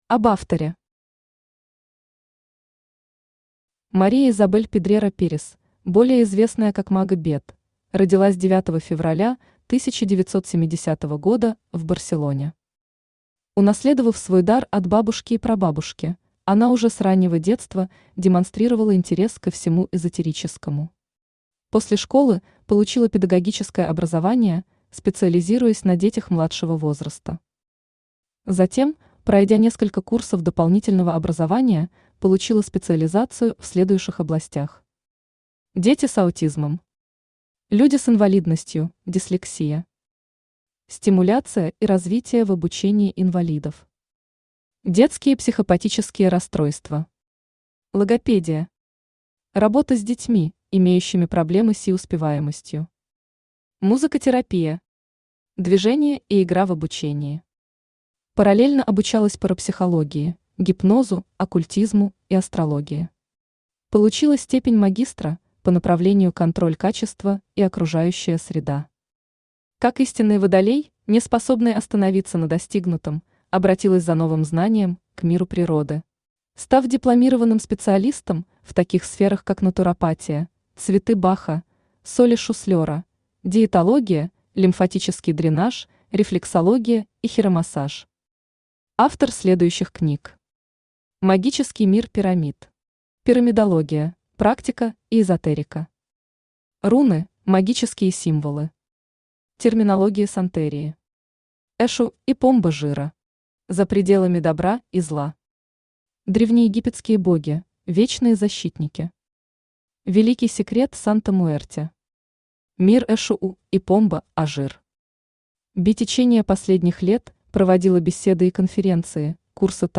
Аудиокнига Великий Секрет Санта Муэрте | Библиотека аудиокниг
Aудиокнига Великий Секрет Санта Муэрте Автор Maribel Pedrera Pérez – Maga Beth Читает аудиокнигу Авточтец ЛитРес.